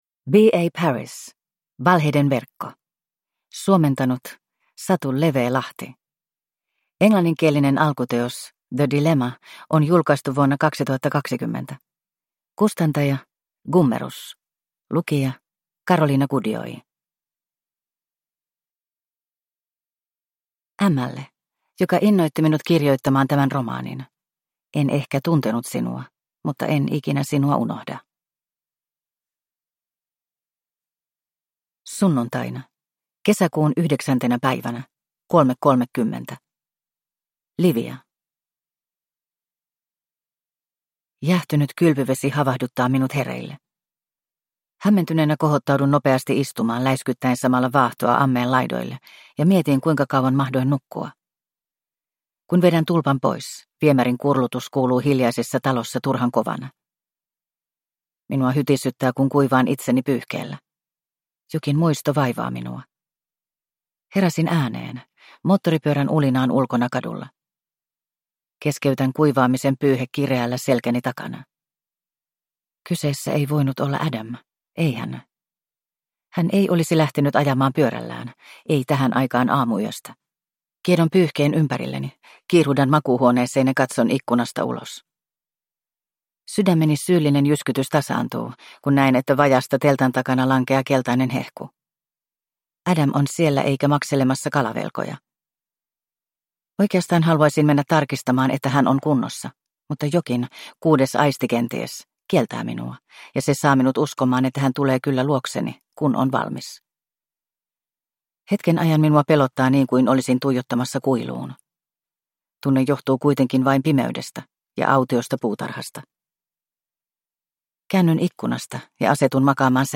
Valheiden verkko – Ljudbok – Laddas ner